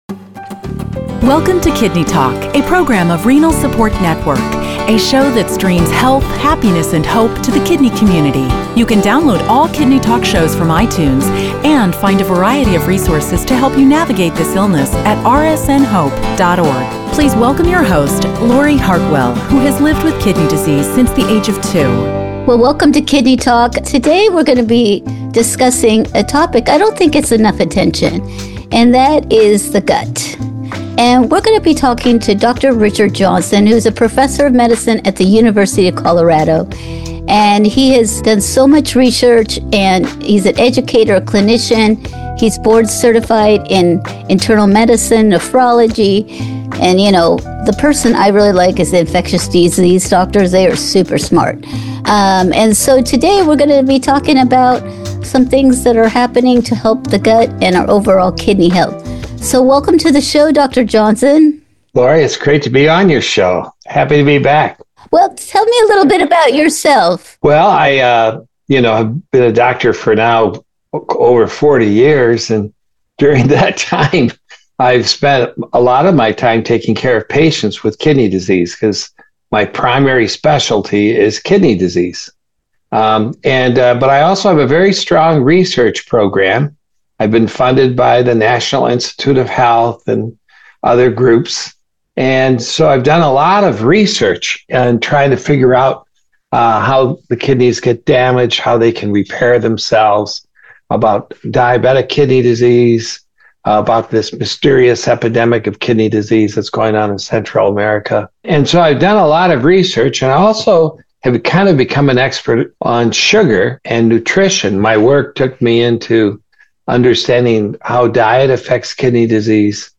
KidneyTalk - An Online Radio Show By Renal Support Network